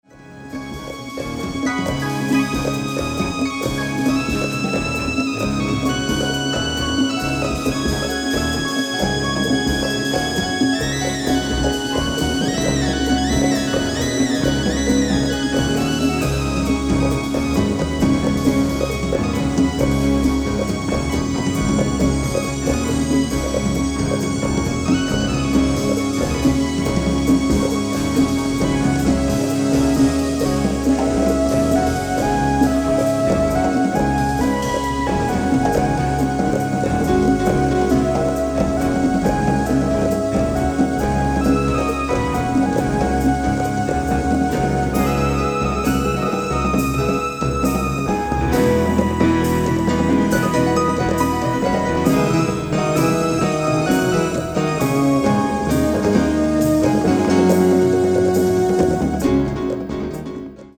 piano, fender rhodes, synthesizers
fretless electric bass
drums